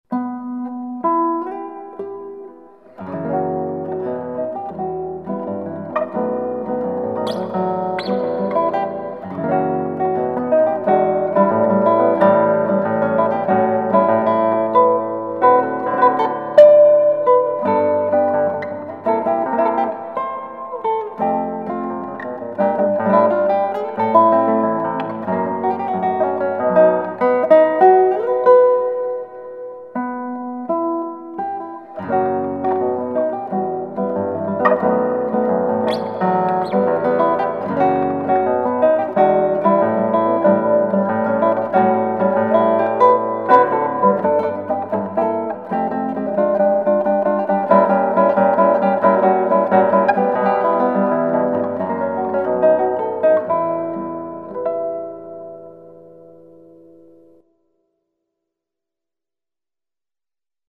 Je�li interesuje Cie troch� muzyka gitarowa